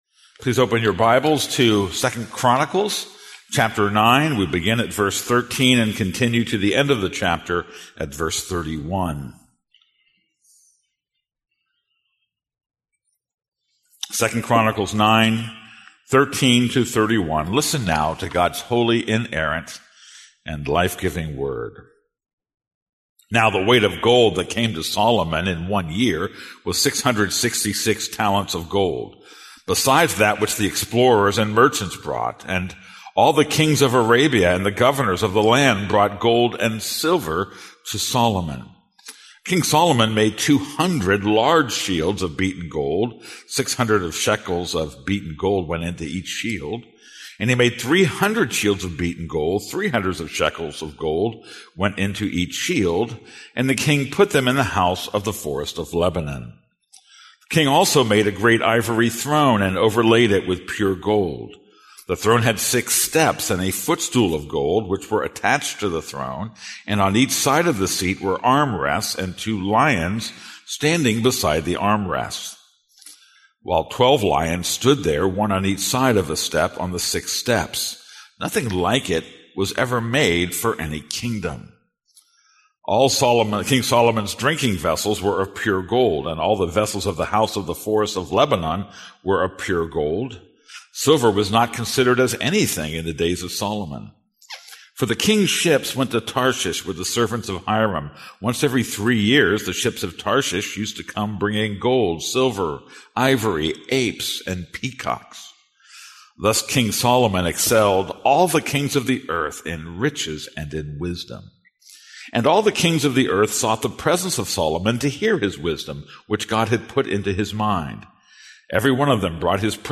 This is a sermon on 2 Chronicles 9:13-31.